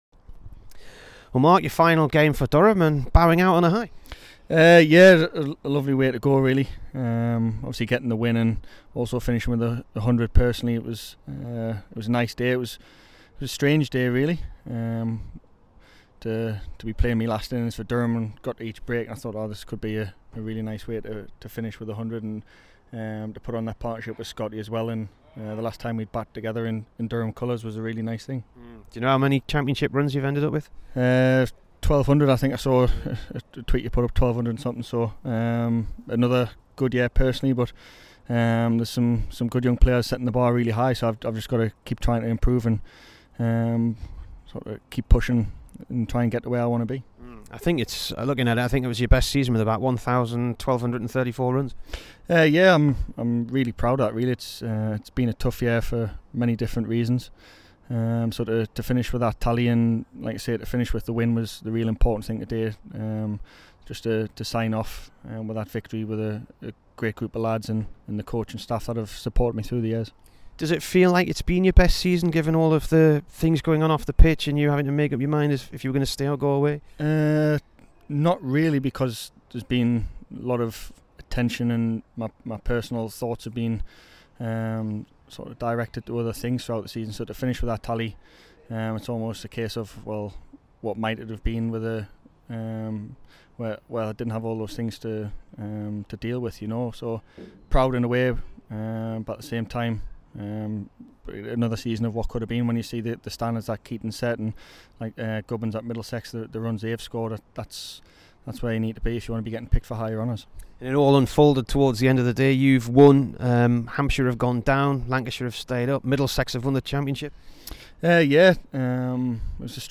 Here's the Durham opener after his century at Hants in his final innings for the club.